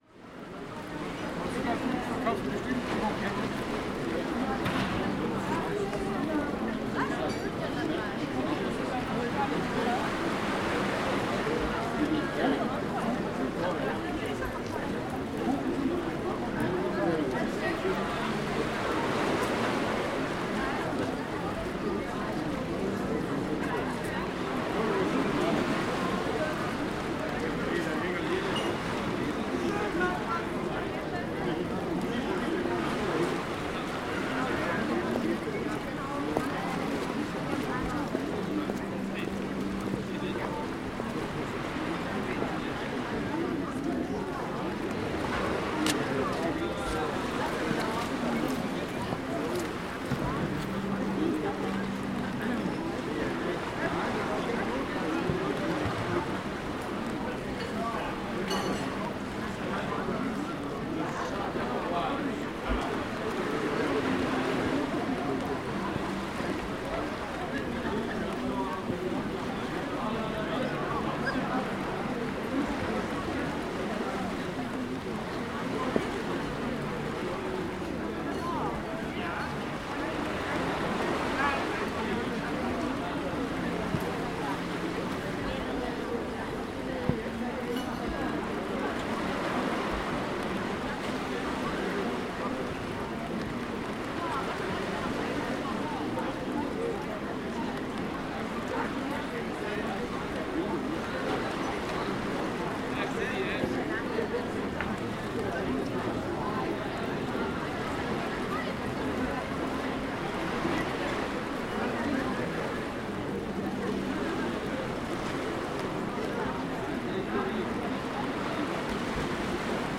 Portofino in the rain